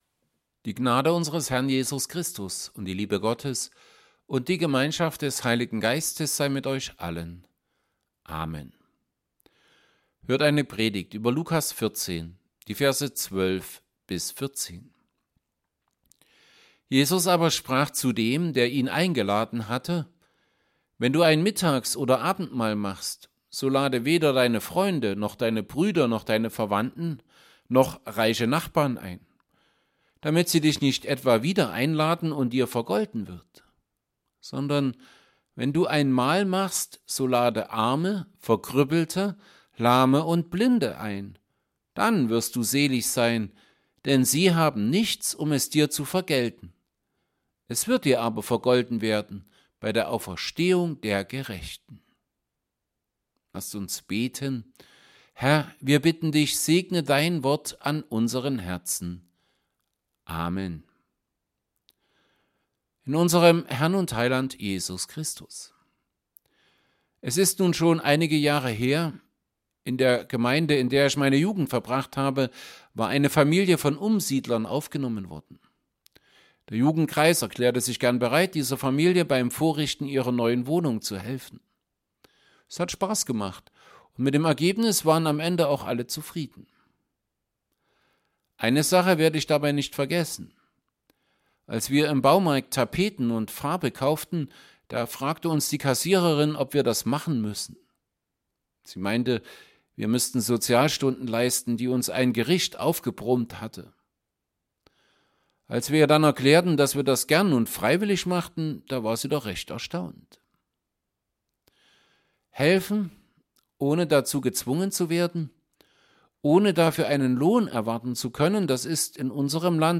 Evangelienpredigten Passage: Luke 14:12-14 Gottesdienst